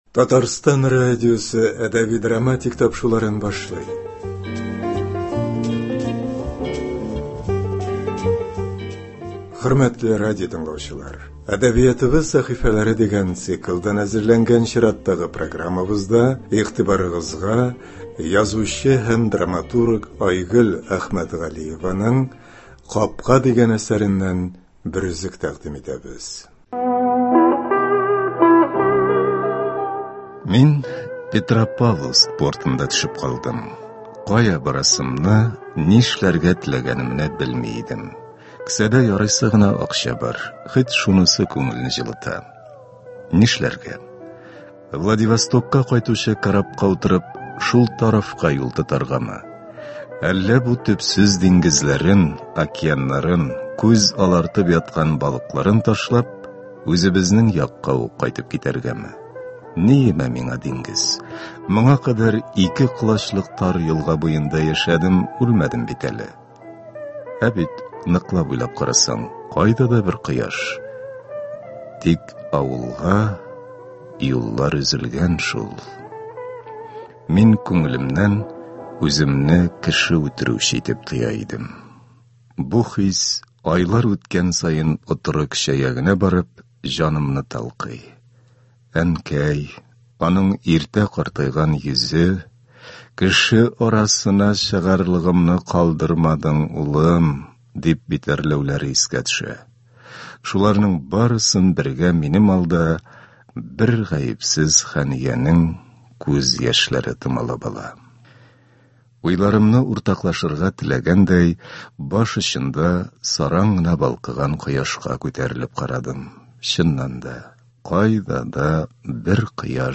“Әдәбиятыбыз сәхифәләре” дигән циклдан әзерләнгән чираттагы программабызда без сезне язучы һәм драматург Айгөл Әхмәтгалиеваның “Капка” дигән әсәреннән бер өзек һәм радиобыз фондыннан шагыйрь Җәүдәт Дәрзаман әсәрләре буенча эшләнгән “Чорлар авазы” дигән радиокомпозиция тыңларга чакырабыз.